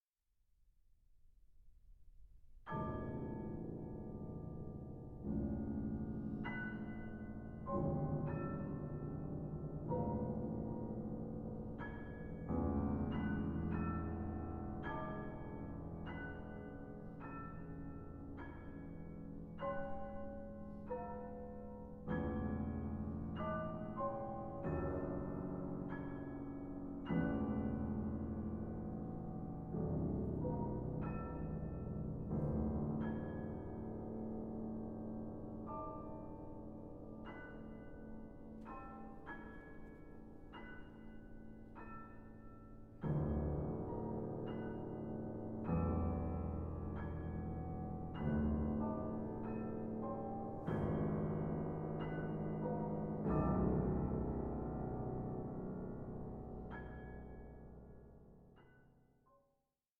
MESMERIZING ACOUSTIC JOURNEY
for two pianos